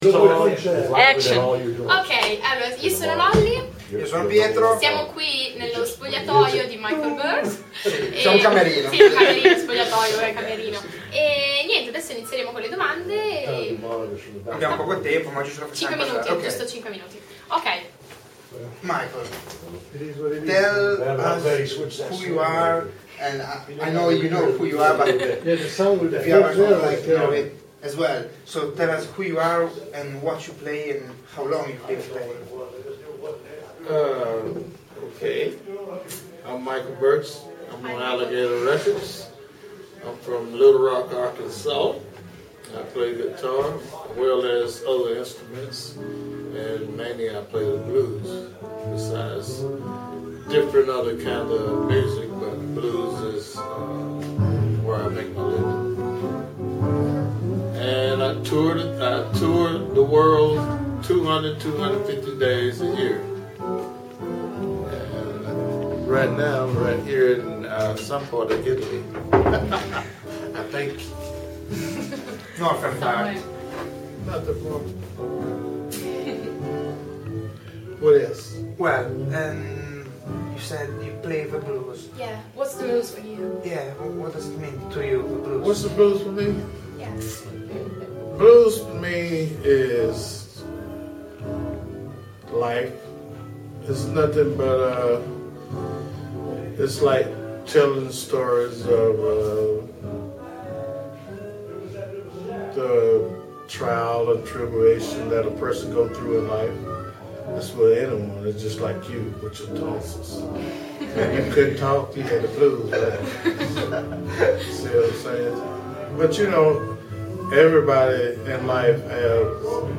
play_circle_filled Intervista a Michael Burks (ultima intervista assoluta rilasciata prima dell'improvviso decesso) Radioweb C.A.G. di Sestri Levante Musicista intervista del 01/05/2012 Intervista al più grande chitarrista Blues contemporaneo del mondo che ci ha concesso gentilmente un'intervista, nonostante le sue condizioni fisiche, presso il Blues & Soul Festival di Sestri Levante.